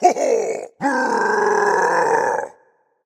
Voice clip from Mario Kart 8